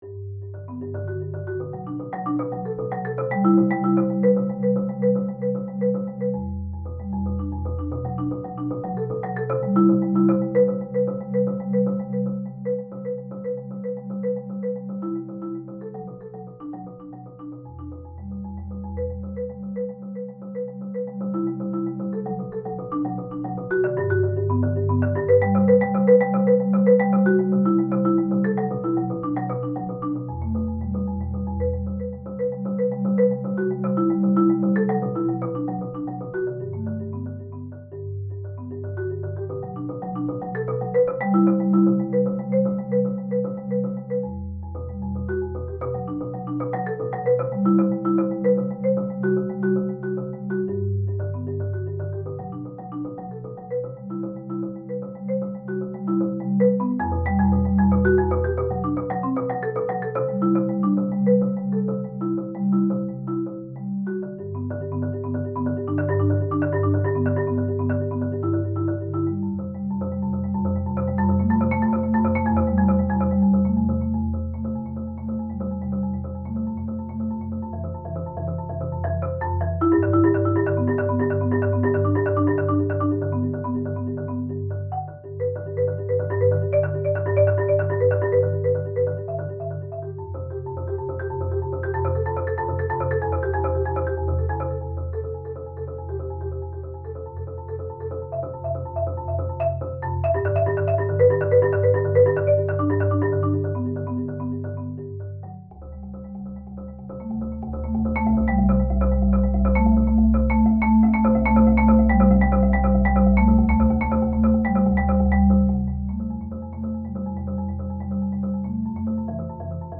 • Instrumentation: Solo marimba (Low C)